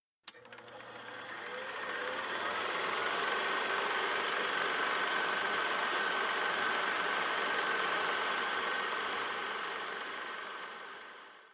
Super 8 Old Movie Projector - Gaming Sound Effect.mp3